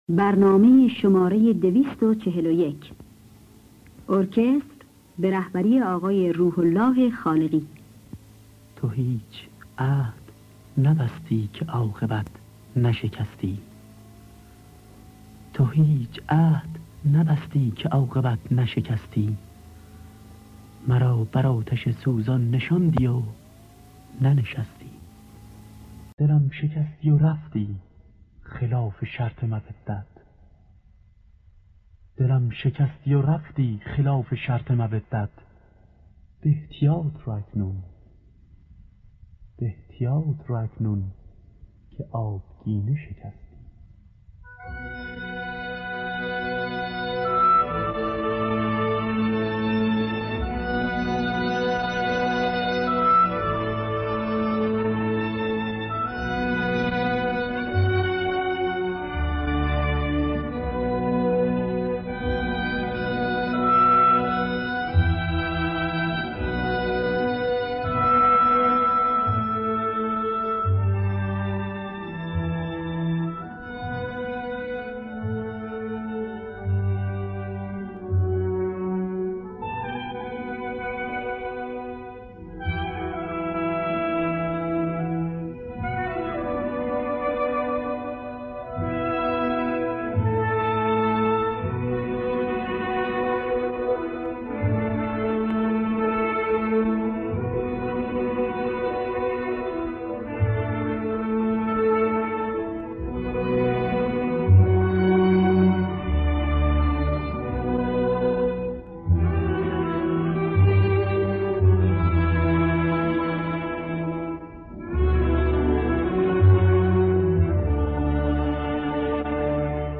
گلهای رنگارنگ ۲۴۱ - دشتی